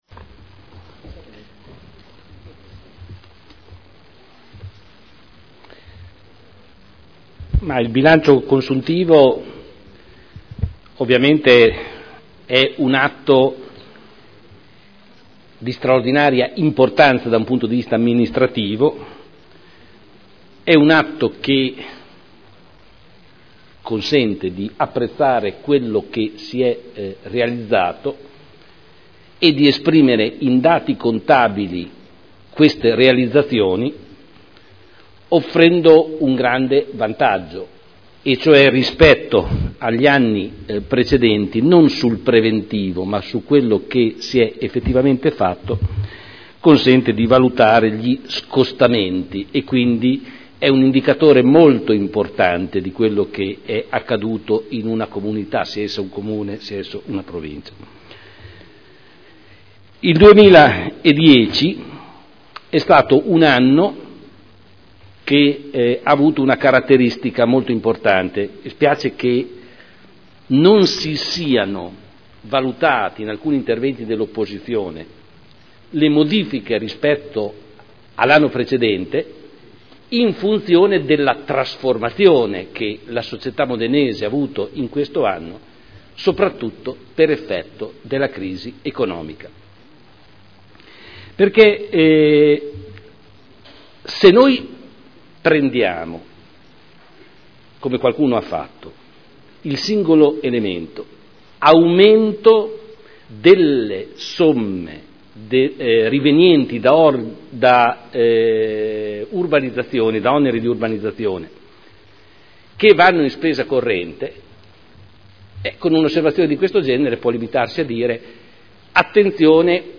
Seduta del 28/04/2011. Dibattito su Delibera: Rendiconto della gestione del Comune di Modena per l’esercizio 2010 – Approvazione (Commissione consiliare del 21 aprile 2011)